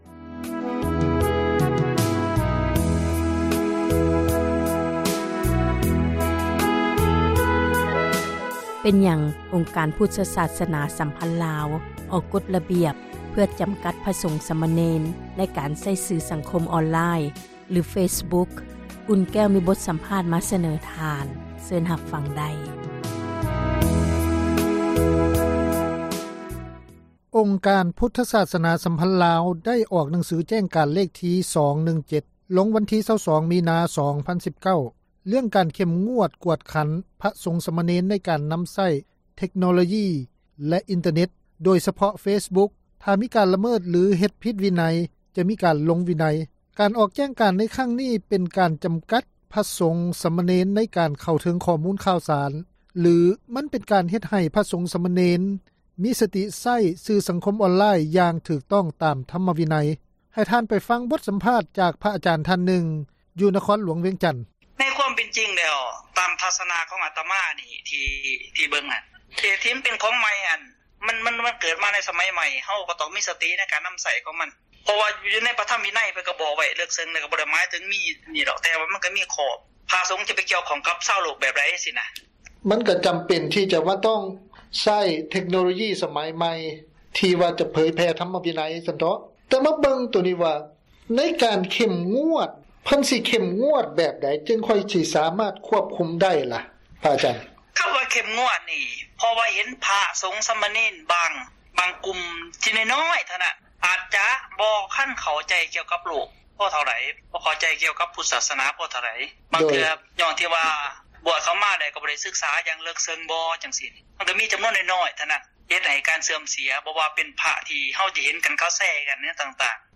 ອັນນັ້ນ ເປັນບົດສຳພາດຂອງ ພຣະອາຈານ ທ່ານນຶ່ງ ກ່ຽວກັບການໃຊ້ສື່ສັງຄົມອອນລາຍ ຂອງພຣະສົງສຳມະເນນ ຊຶ່ງມີຂໍ້ຄວາມວ່າ ພຣະສົງສຳມະເນນ ຄວນໃຊ້ສື່ສັງຄົມອອນລາຍ ຫຼື ເຟສບຸຄຢ່າງມີສະຕິ ຕາມຄວາມເໝາະສົມ ທາງທຳມະວິນັຍ.